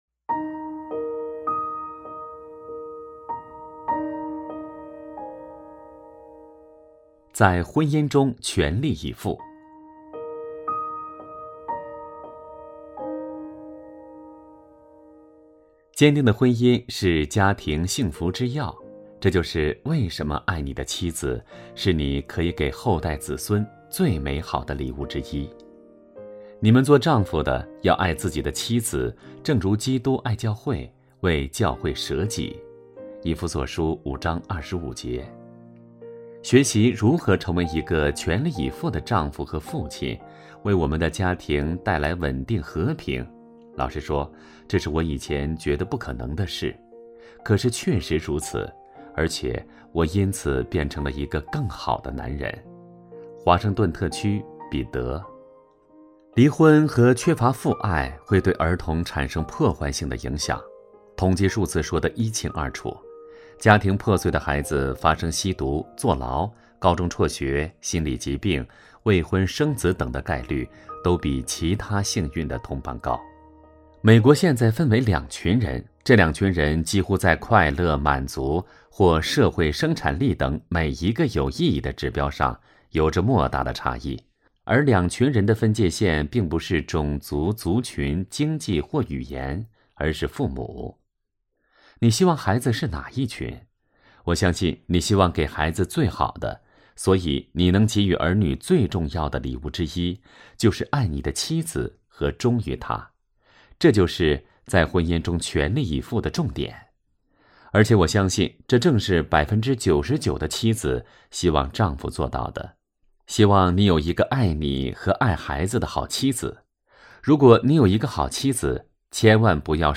首页 > 有声书 > 婚姻家庭 | 成就好爸爸 | 有声书 > 成就好爸爸：11 在婚姻中全力以赴